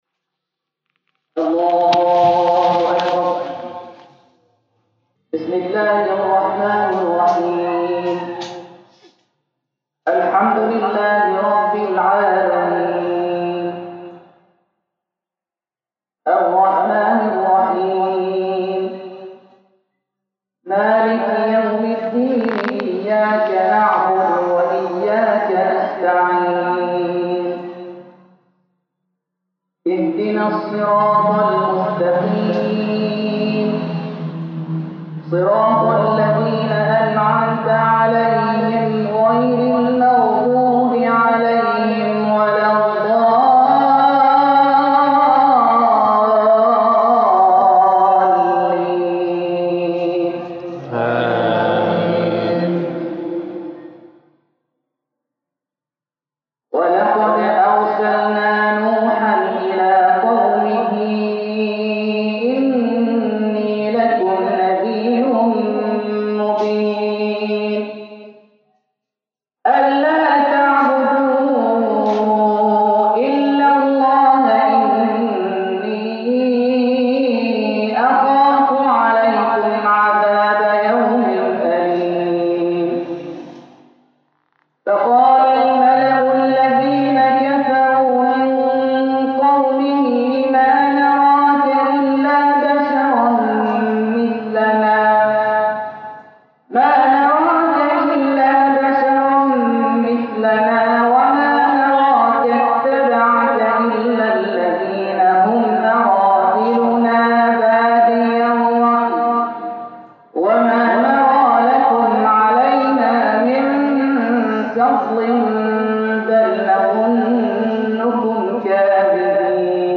تلاوة مباركة
في تراويح رمضان لهذا العام في القلمون.